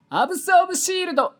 呪文 魔法 ボイス 声素材 – Magic Spell Voice